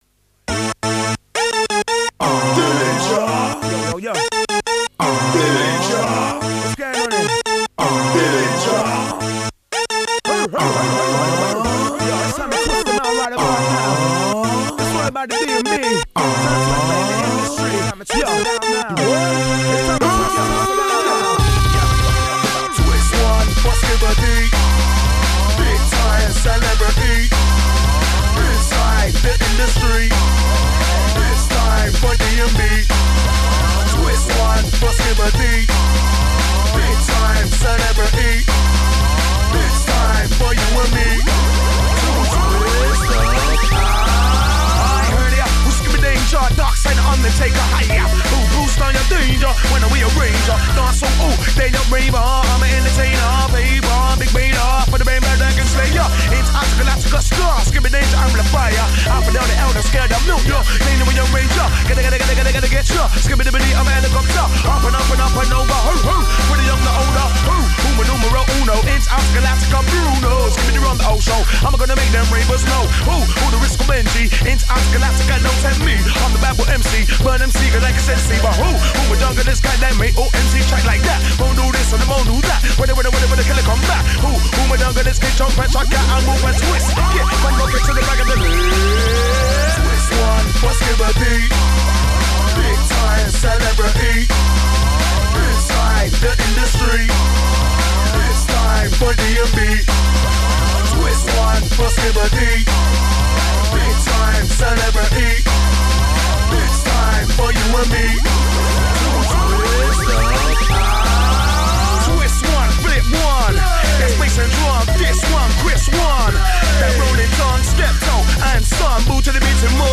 Tags: Metal Drum n bass hip hop